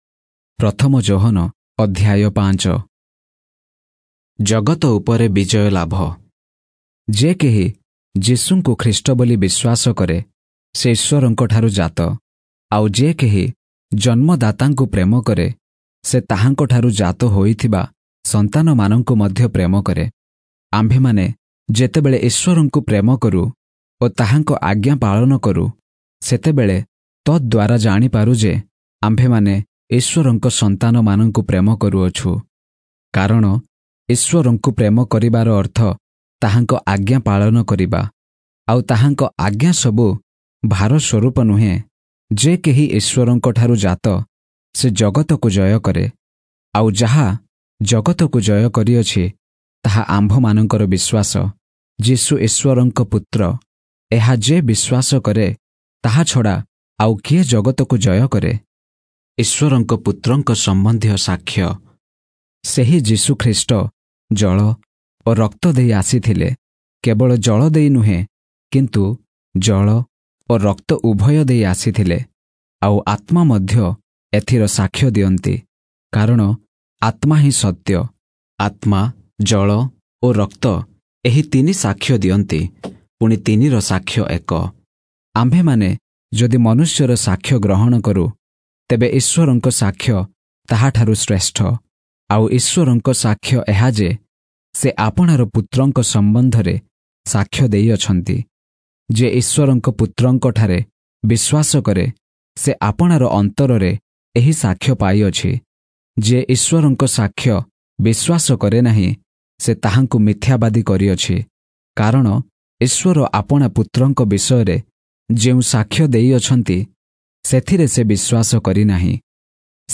Oriya Audio Bible - 1-John 4 in Irvor bible version